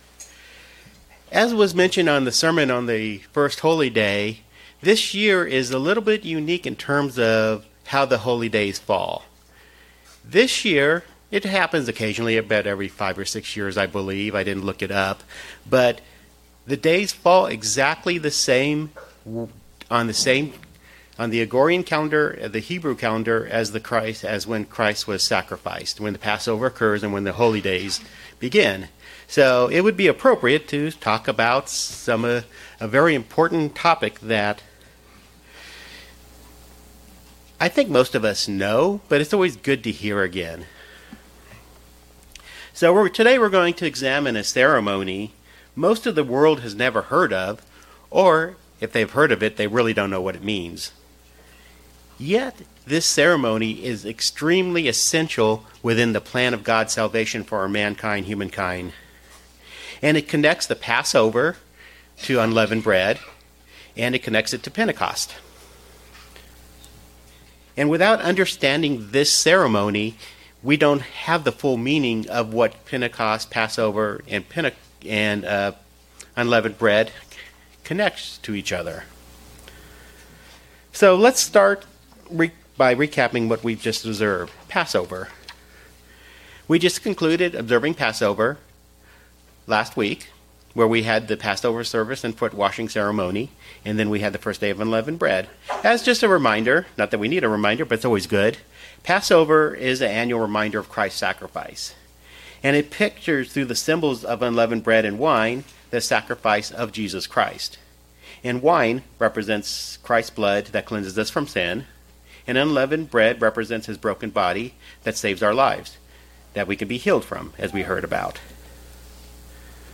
This sermonette examines a ceremony which is extremely essential to the plan of salvation for mankind. It connects the Passover to the days of Unleavened Bread and to Pentecost.
Given in Springfield, MO